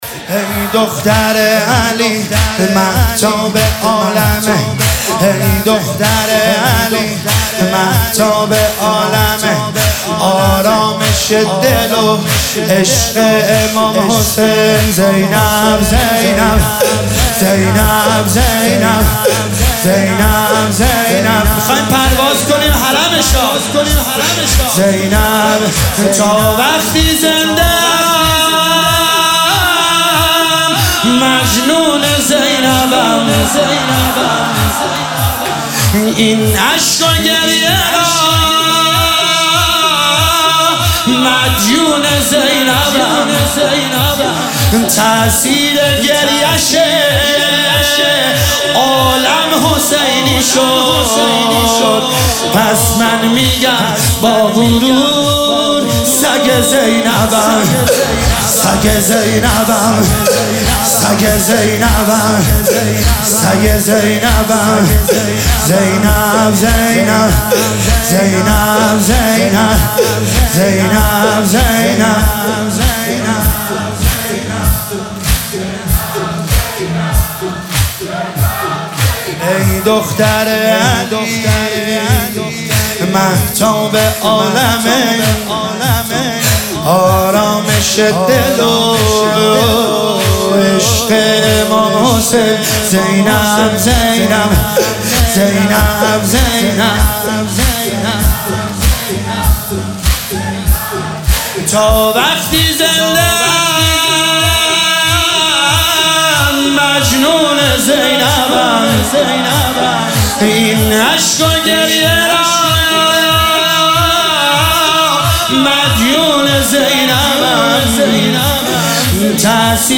دانلود فایل صوتی مداحی زمینه حضرت زینب